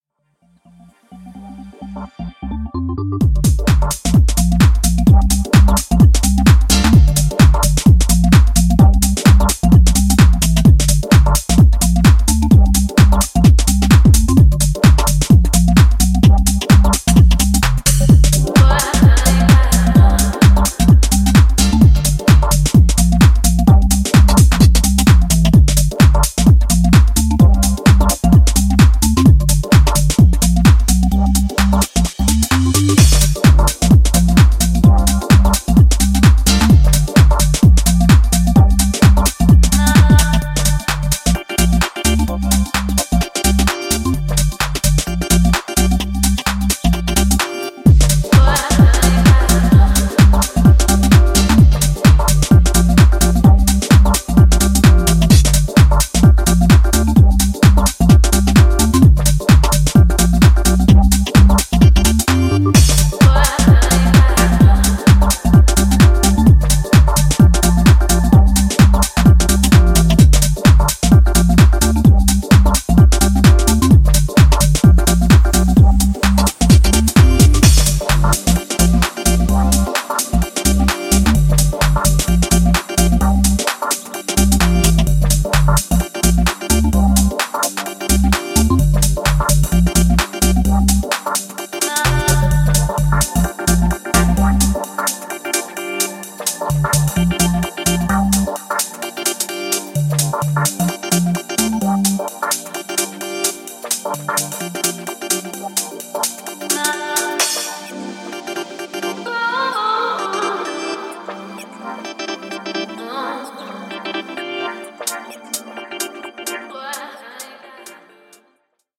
ジャンル(スタイル) HOUSE / TECH HOUSE